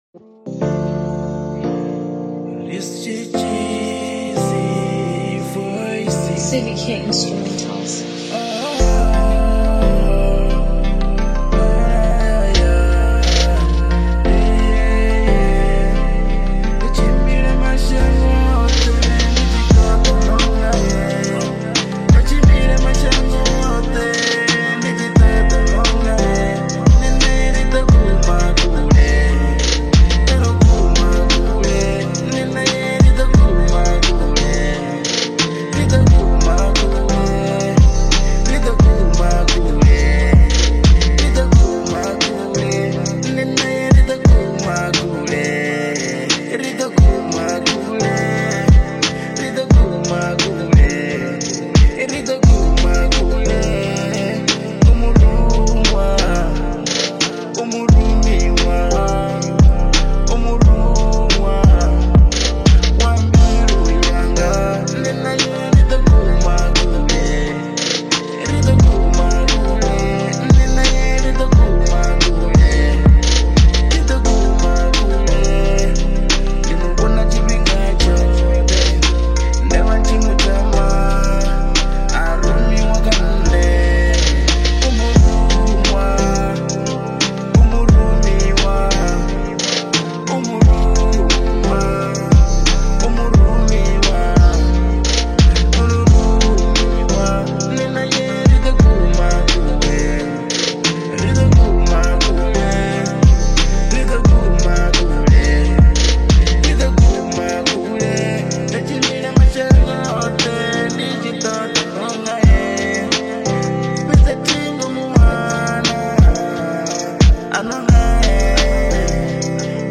3 months ago R & B